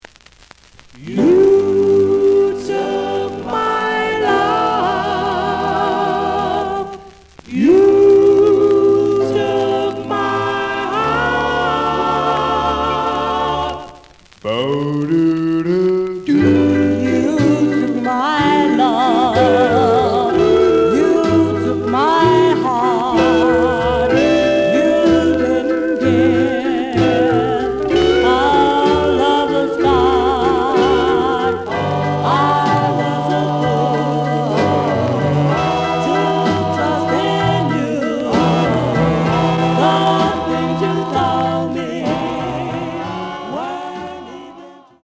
試聴は実際のレコードから録音しています。
●Genre: Rhythm And Blues / Rock 'n' Roll
●Record Grading: G+ (両面のラベルにダメージ。A面のラベルに書き込み。盤に歪み。プレイOK。)